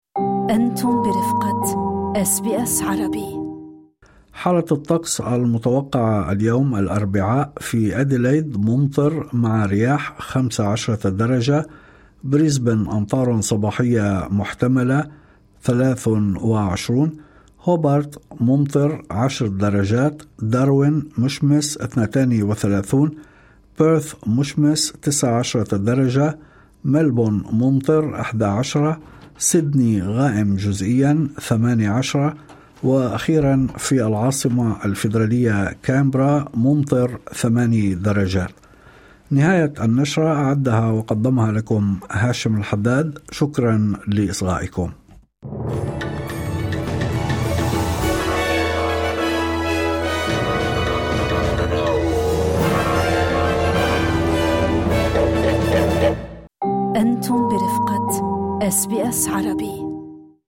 يمكنكم الاستماع الى النشرة الاخبارية كاملة بالضغط على التسجيل الصوتي أعلاه.